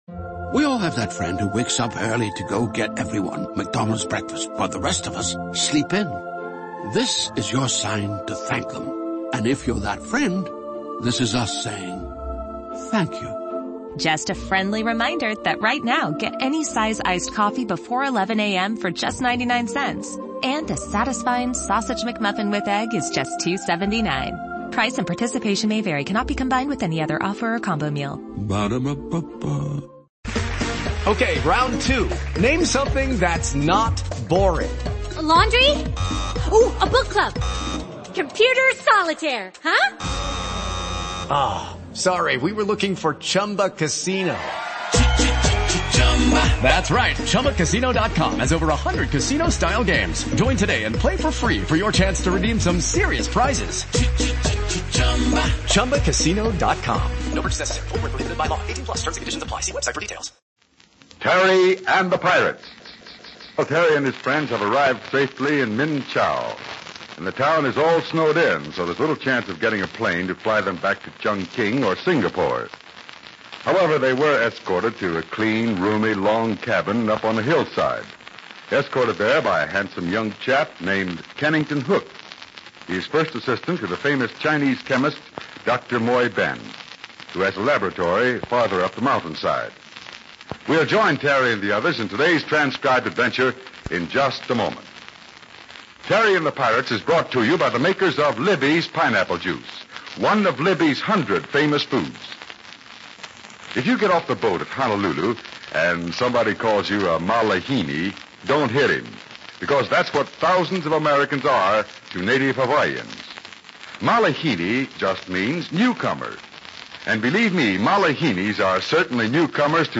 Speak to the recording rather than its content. The radio series was first broadcast on NBC Red Network on November 1, 1937.